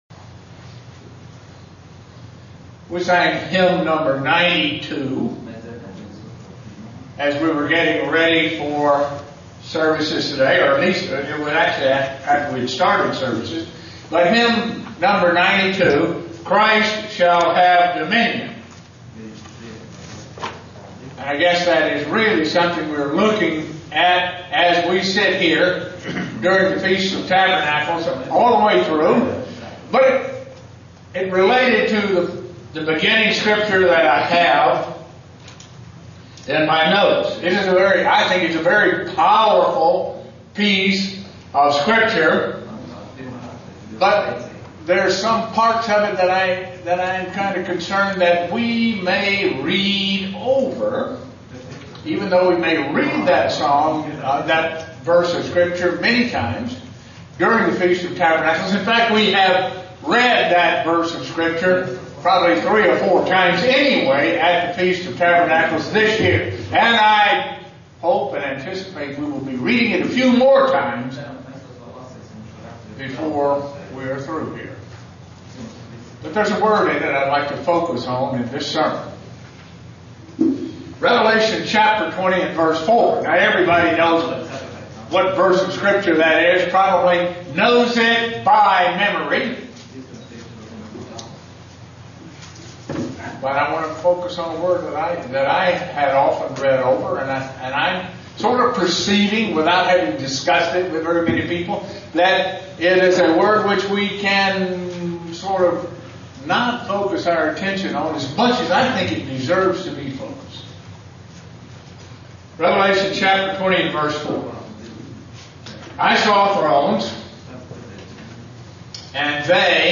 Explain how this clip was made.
The fifth day of the Feast of Tabernacles in Estonia SEE VIDEO BELOW Given in Buffalo, NY